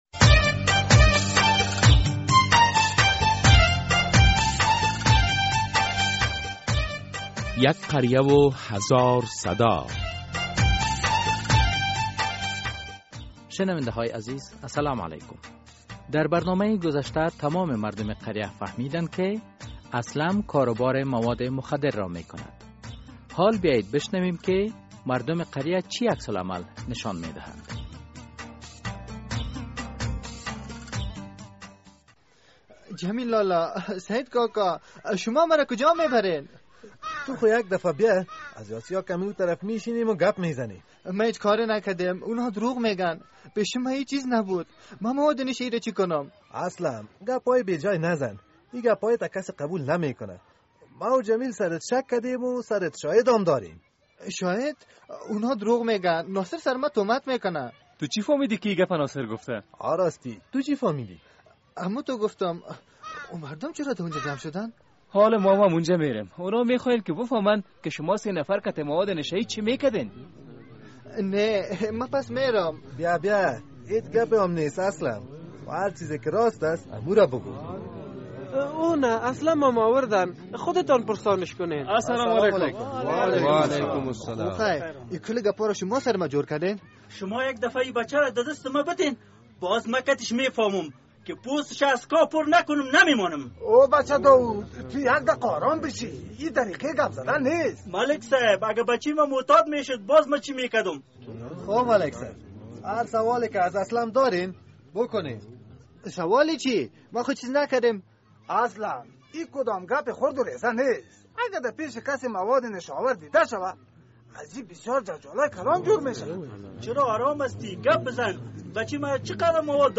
در این درامه که موضوعات مختلف مدنی، دینی، اخلاقی، اجتماعی و حقوقی بیان می گردد هر هفته به روز های دوشنبه ساعت ۳:۳۰ عصر از رادیو آزادی نشر می گردد.